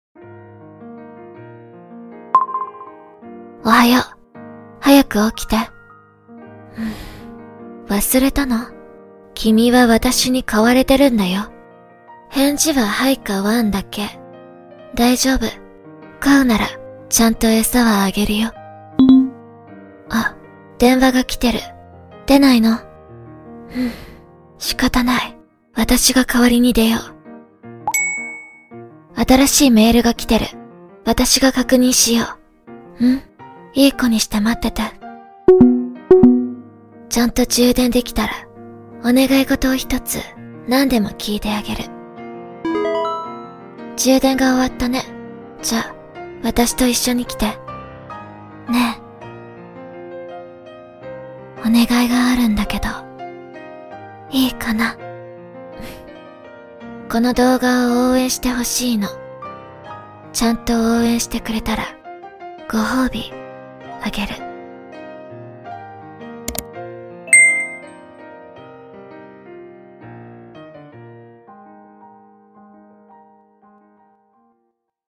• Качество: высокое
Будильник говорит с тобой по-японски